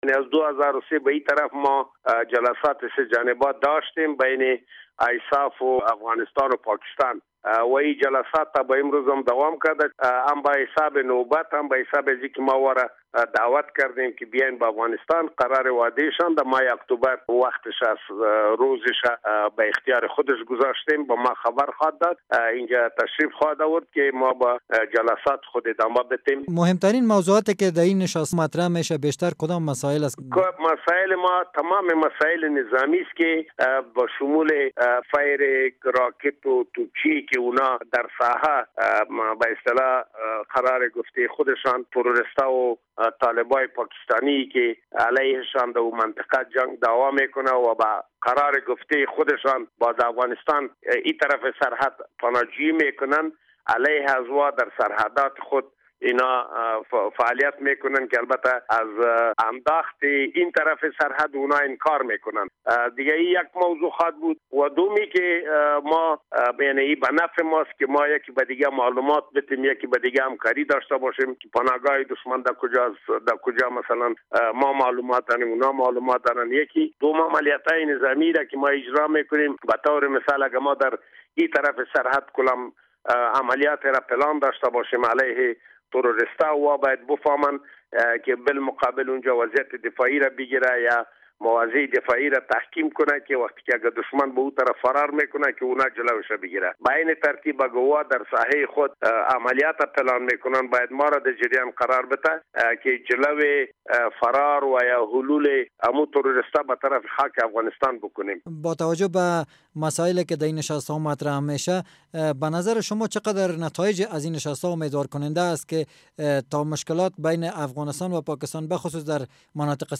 مصاحبه با شیرمحمد کریمی در مورد مسافرت لوی درستیز پاکستان به افغانستان